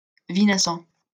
Vinassan (French pronunciation: [vinasɑ̃]